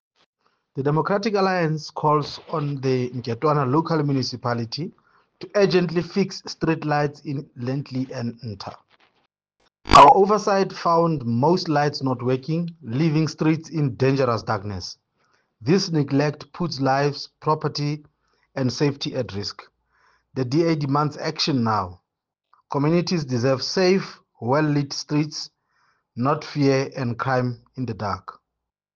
Sesotho soundbites by Cllr Diphapang Mofokeng.